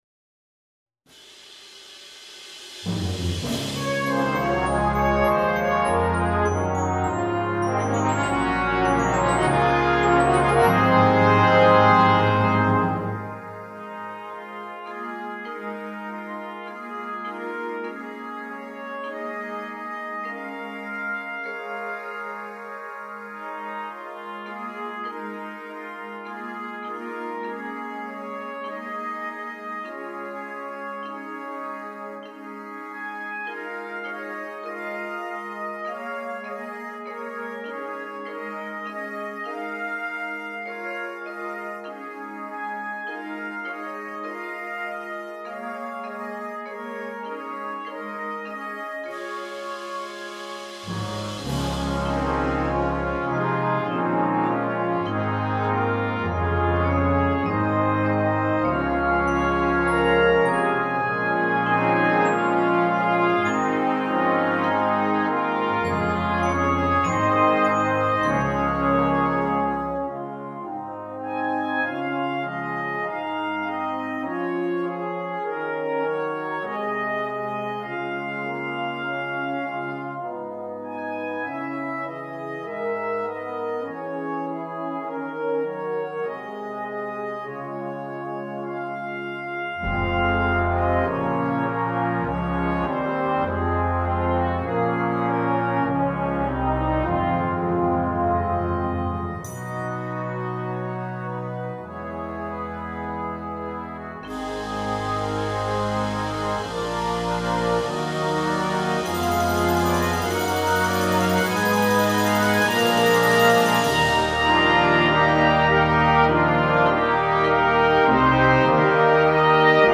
Besetzung: Soprano Cornet Solo & Brass Band